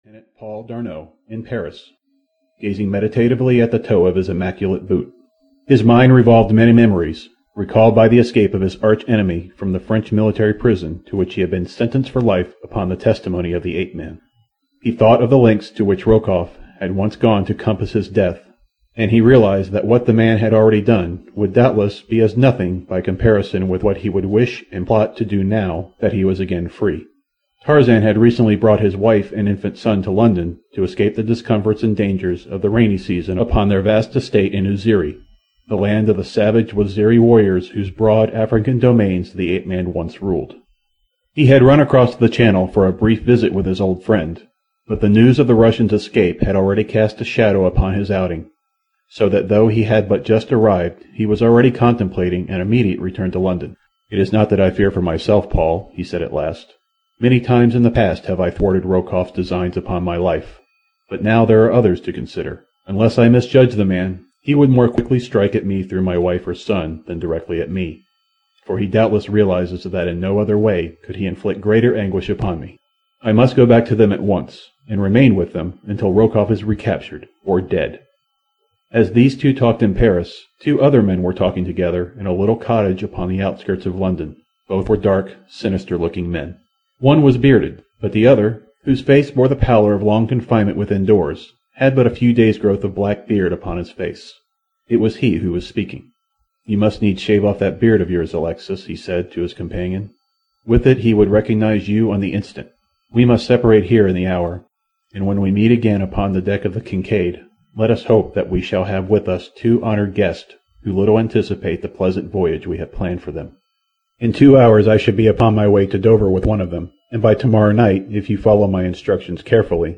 The Beasts of Tarzan (EN) audiokniha
Ukázka z knihy